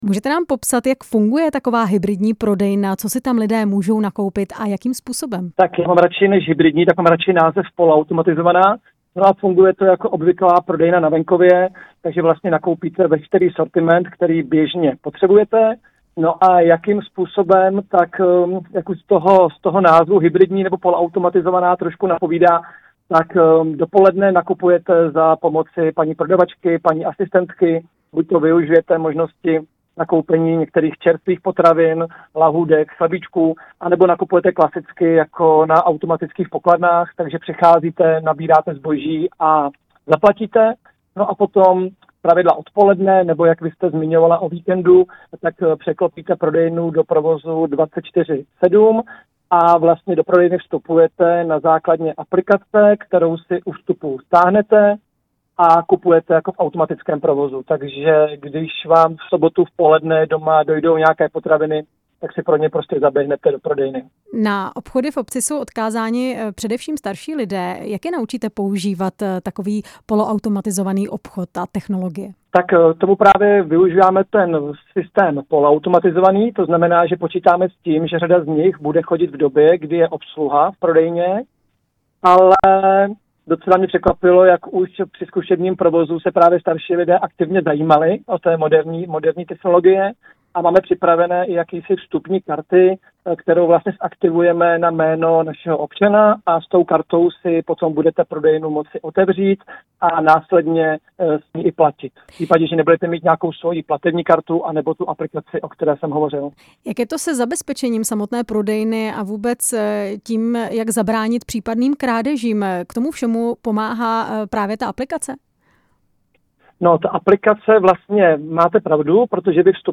Rozhovor se starostou Vysočiny Tomášem Dubským